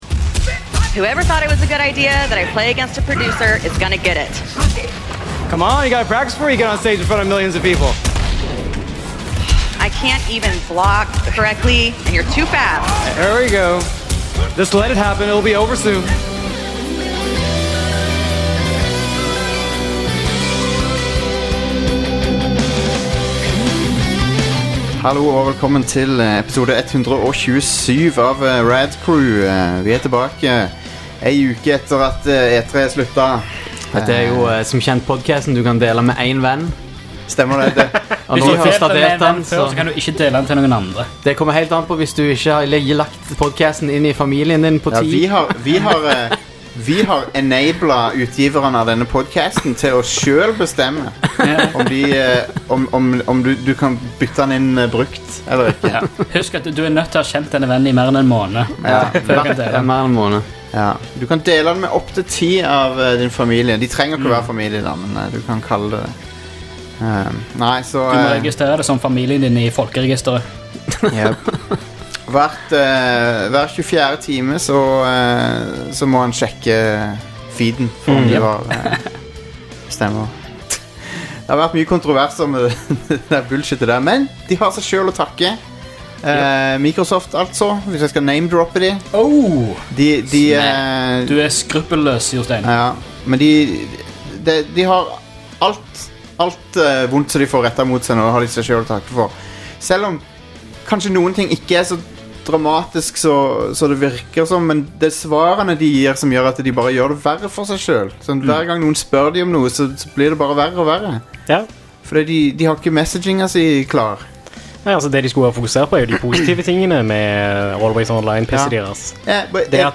Gutta forteller hvordan spillopplevelsen traff dem rett i hjertet, men at det er viktig å være i riktig humør for å spille det i lengre strekk.